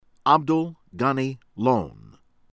LAXMAN, BANGARU BAHN-gah-roo    LAHKSH-mahn